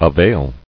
[a·vail]